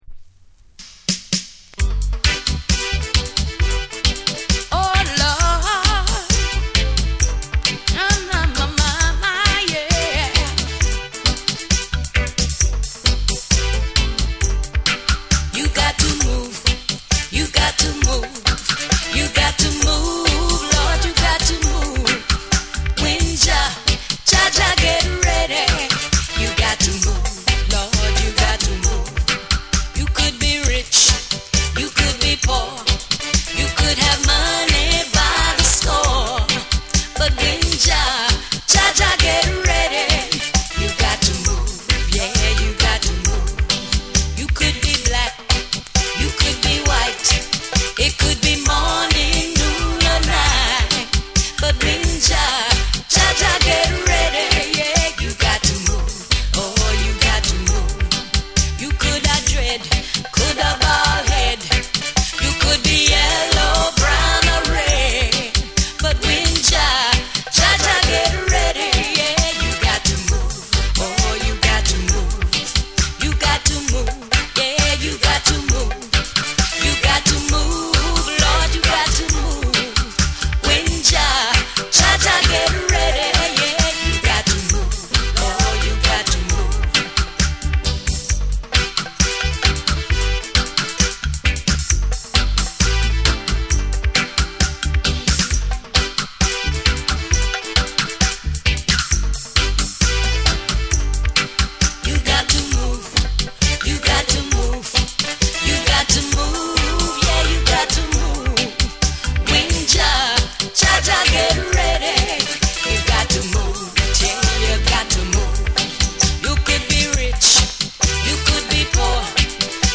温かく力強いヴォーカルと深いグルーヴが融合し、心を揺さぶるレゲエを収録。
REGGAE & DUB